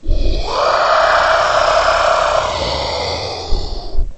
龙的声音 " 龙的咆哮声 呼吸声 11
描述：为制作史瑞克而制作的龙声。使用Audacity录制并扭曲了扮演龙的女演员的声音。
Tag: 生物 发声 怪物